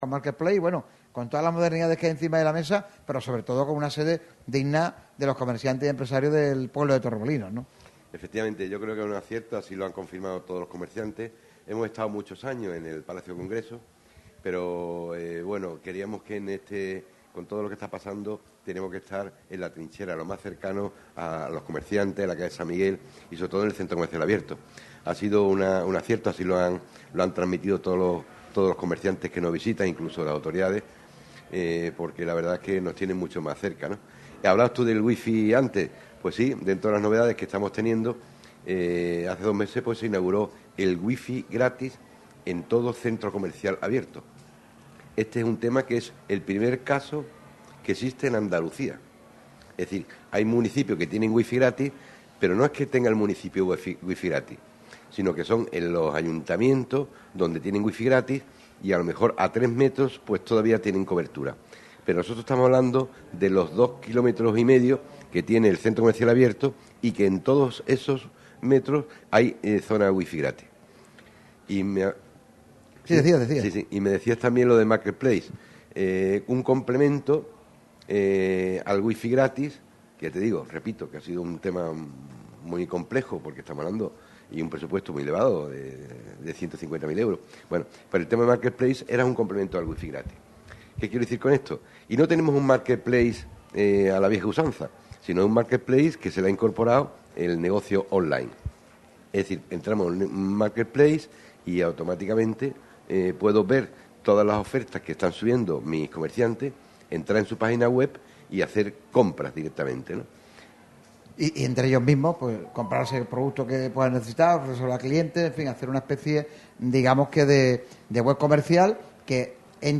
Radio MARCA Málaga realizó un programa especial en ACET con motivo de la Feria de San Miguel